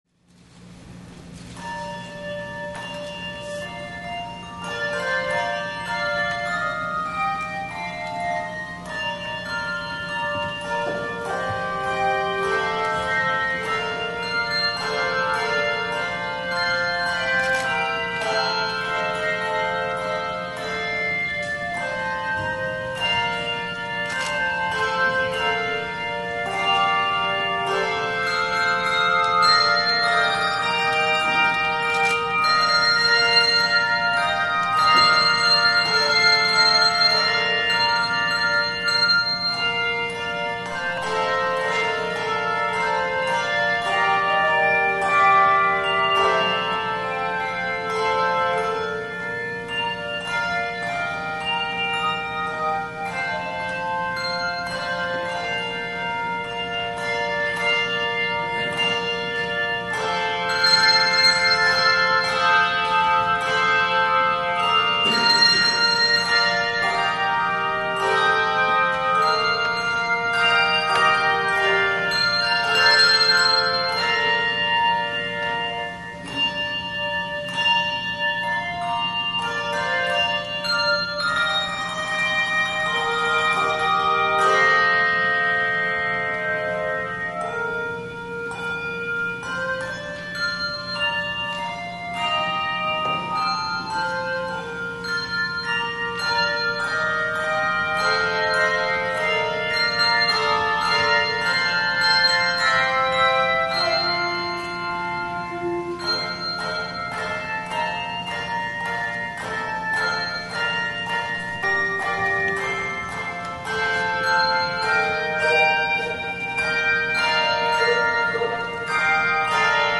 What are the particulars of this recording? THE OFFERTORY